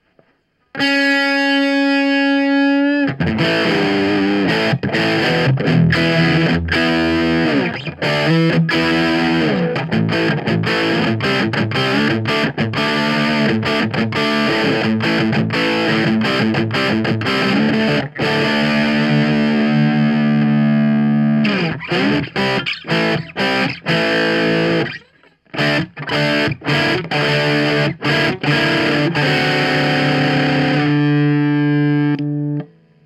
Humbucker.mp3